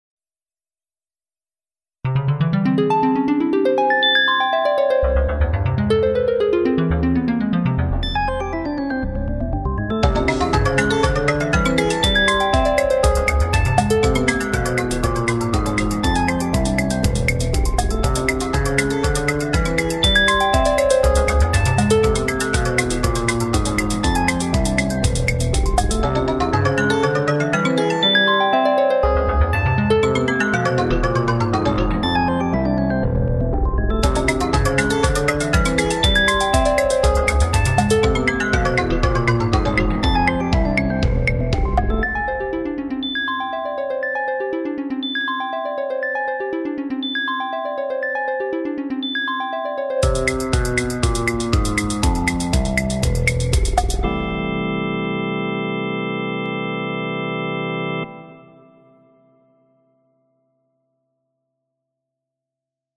A wacky, comic synthesizer piece.